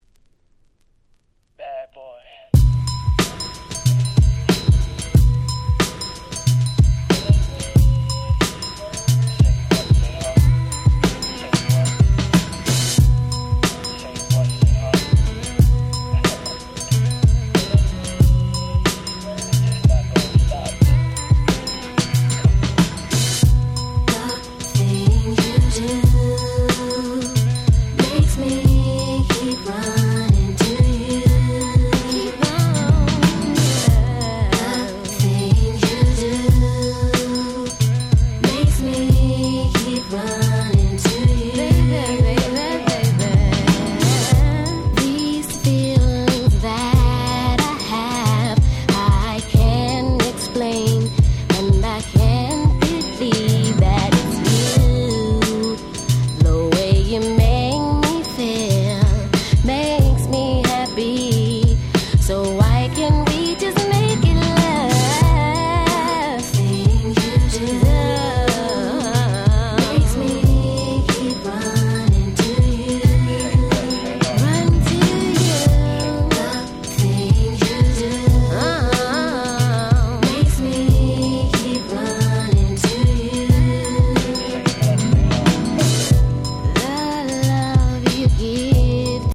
Nice Hip Hop Soul♪